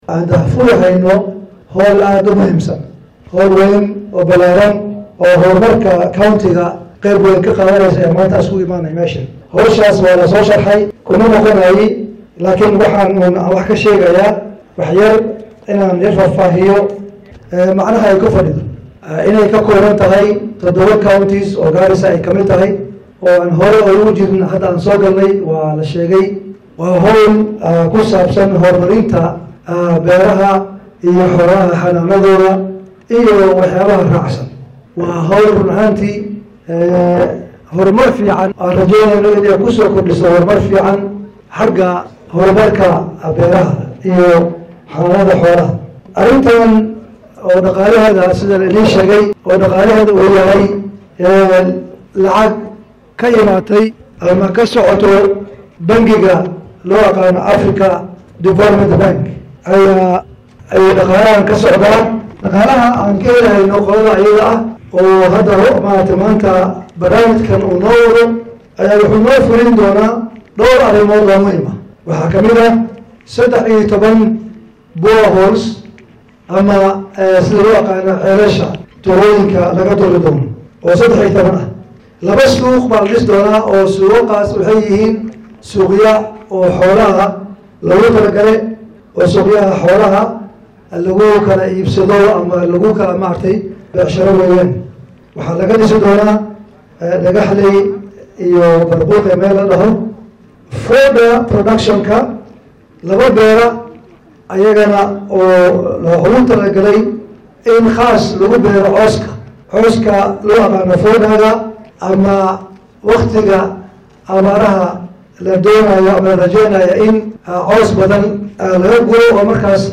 Barasaabka Garissa oo ka hadlay xaflad maanta la qabtay ayaa sheegay in barnaamijkan u yahay mid aad loogu bahnaa.